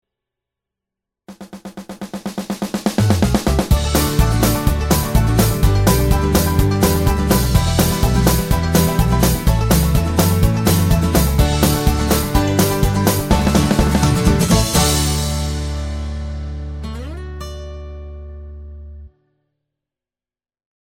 Explicit Backing Vocals Comedy/Novelty 1:11 Buy £1.50